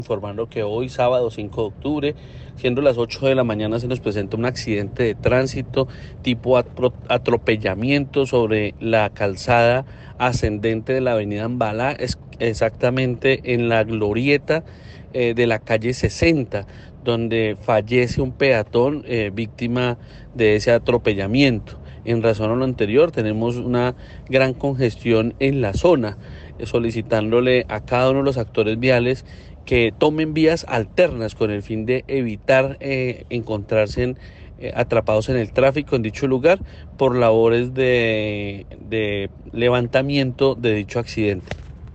“Allí lamentablemente informamos a los ibaguereños que fallece un peatón, victima de atropellamiento”, expresó el Ricardo Fabián Rodríguez, secretario de Movilidad.
Ricardo-Rodriguez-Secretario-de-Movilidad-Cierre-vial-por-siniestro-vial.-.mp3